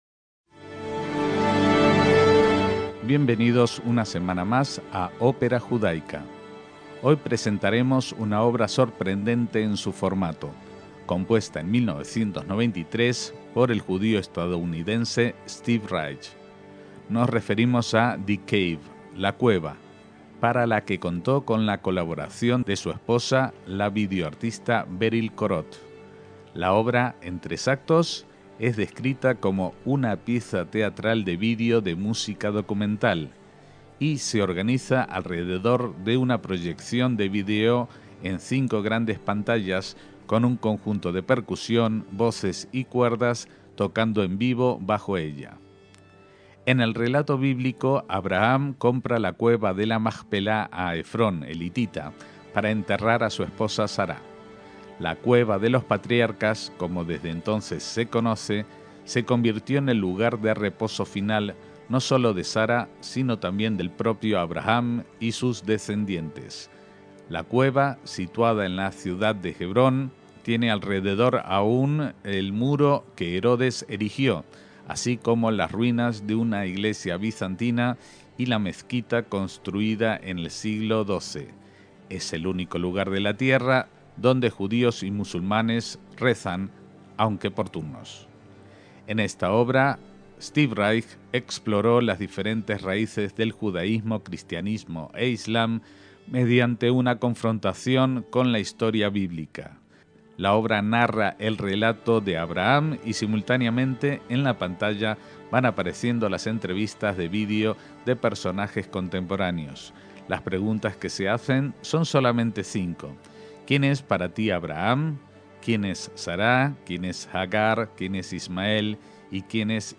ÓPERA JUDAICA